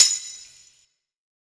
014_Lo-Fi Ambient Perc.wav